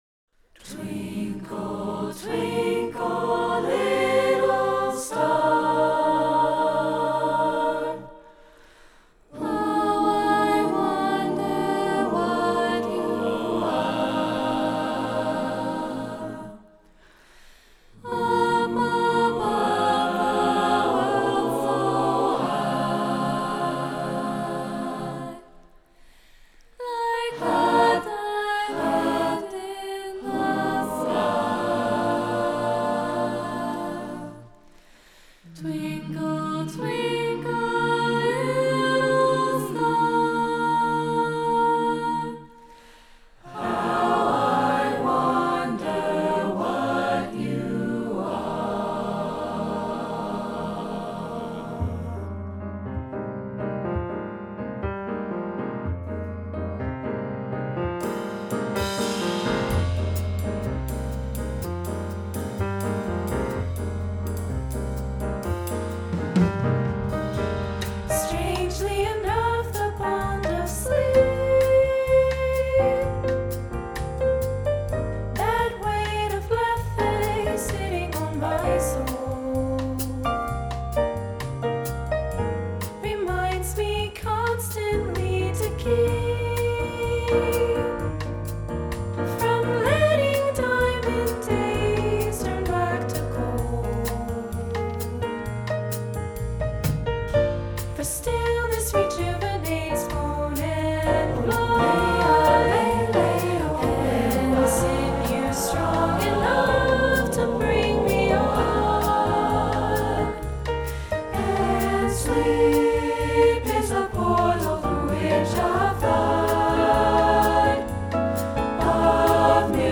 features lush harmonic textures